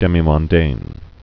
(dĕmē-mŏn-dān, -mŏndān)